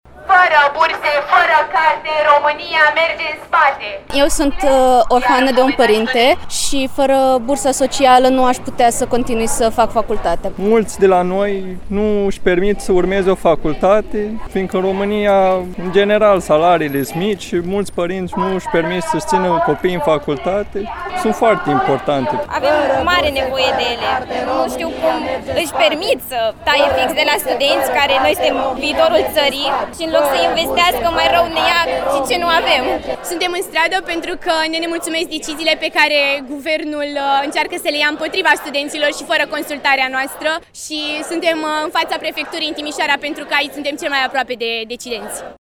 Peste 100 de studenți au pichetat azi Palatul Administrativ din Timișoara, pentru a atrage atenția asupra deciziei guvernului de a reduce bugetul pentru burse.
Vox-studenti.mp3